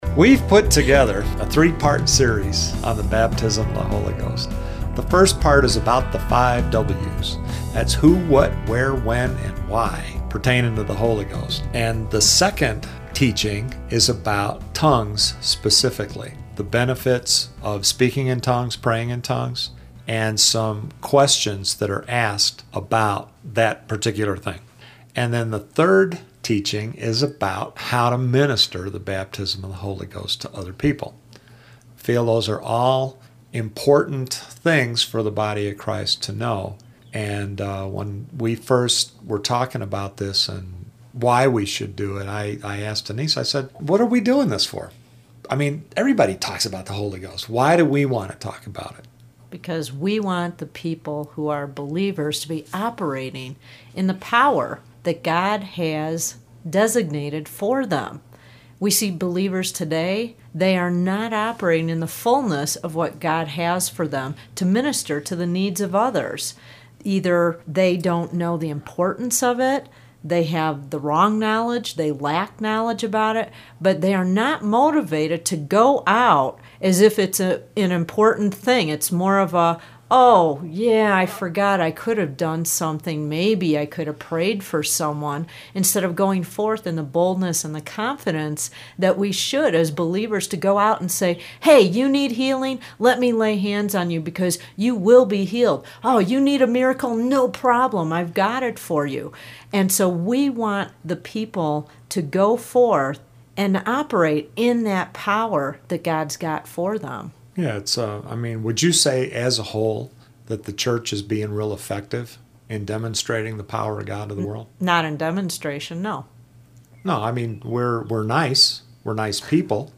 This foundational series is recorded in an intimate, conversational setting that is comprehensive, yet easy to understand.